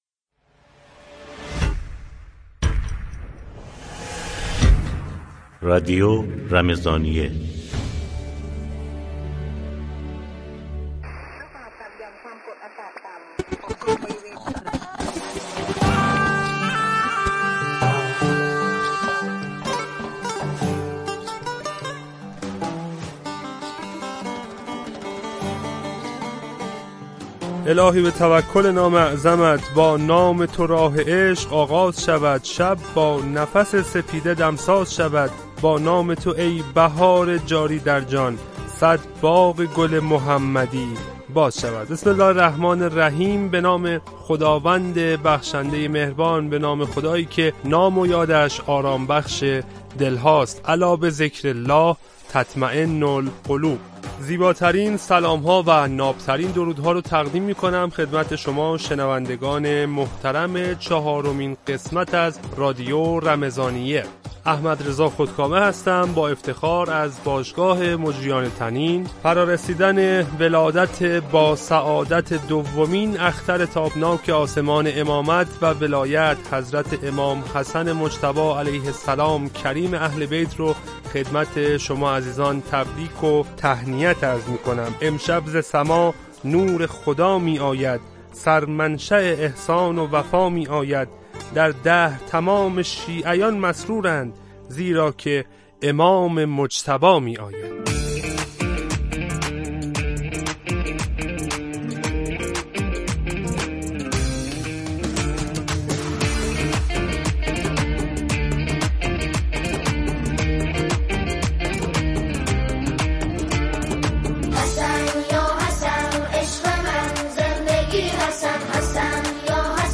مصاحبه‌ی هفت‌برکه